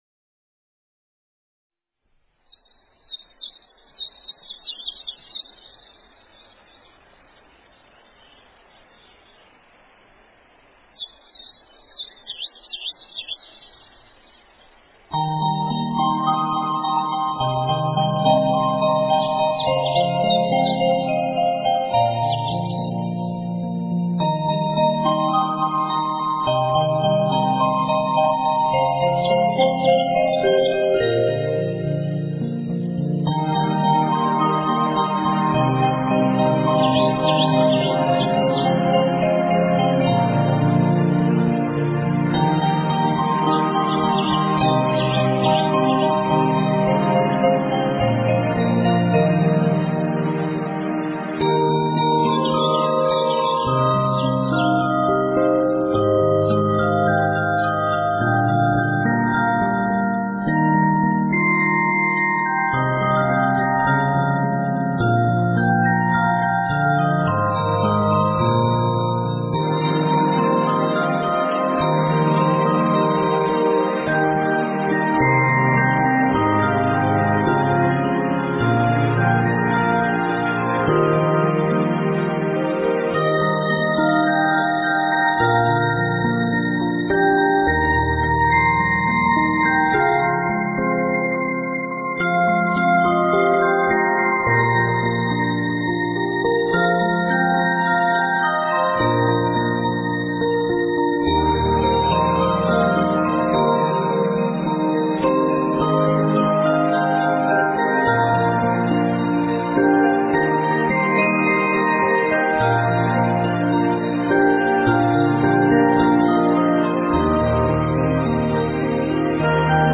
标签: 佛音 冥想 佛教音乐